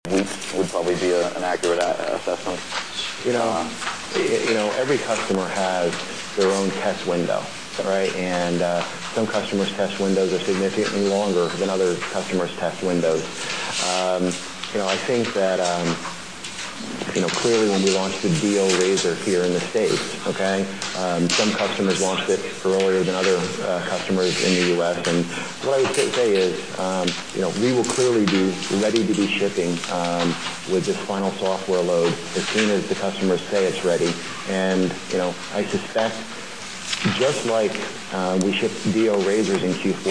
また、テープ起こしの障害となる雑音を軽減するための特殊なソフトウェアを使用し、小さい声の音も大きな音で聞くことができます。以下のリンクで、実際のソフトウェア使用前と使用後の音源を聞き比べてみてください。
voxtab_bad_audio.mp3